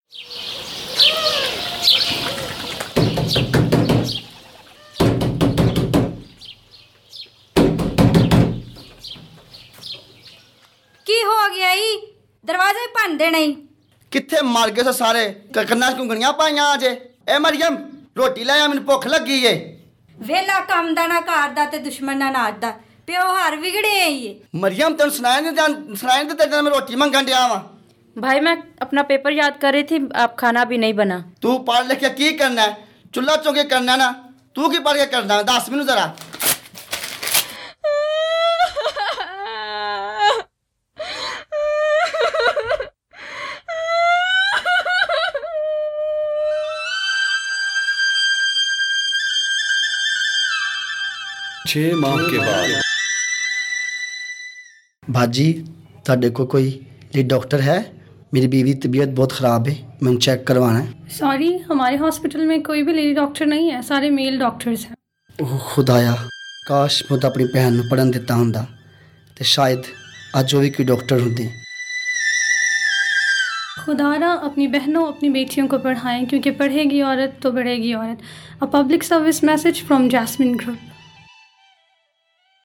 This CSA (Community Service Announcement) created by the Jauharabad group, advocates for girls’ education. A hungry man is angry with his sister for not cooking and he rips up her study books. 6 months later he takes his wife to hospital and insists on her being treated by a female doctor.
Jauharabad-CSA-Girls-Education.mp3